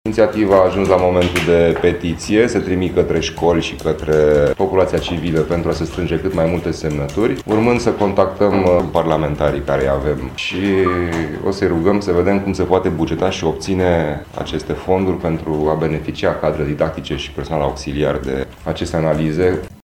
Președintele Comisiei pentru pentru Sănătate, Protecția Copilului și a Persoanelor Adulte cu Handicap, din Cadrul Consiliului Județean Brașov, Andi Kadas, a prezentat într-o conferință de presă o inițiativă socială.  Prin aceasta, se dorește efectuarea de analize gratuite pentru toți dascălii și personalul auxiliar, înainte de debutul fiecărui an școlar, cu costuri decontate prin CAS.
Andi Kadas, președintele Comisiei pentru pentru Sănătate, Protecția Copilului și a Persoanelor Adulte cu Handicap: